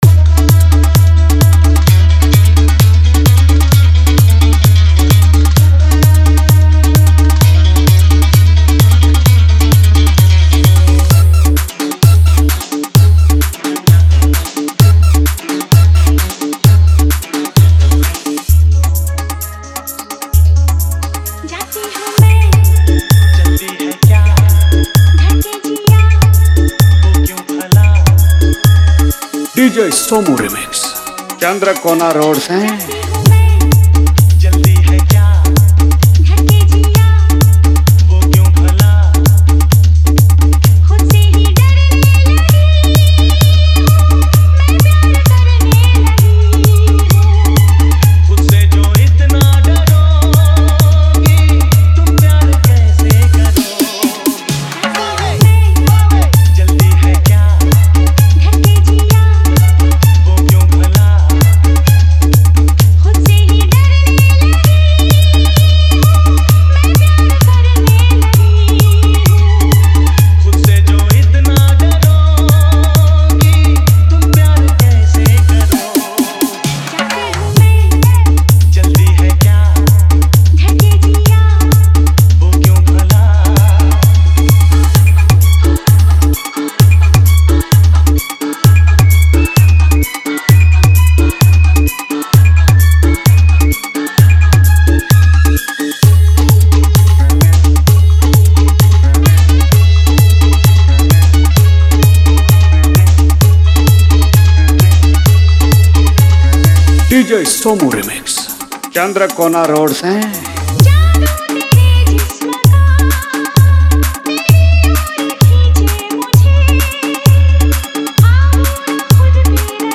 Durga Puja Special Romantic Hindi Hummbing Love Mix 2024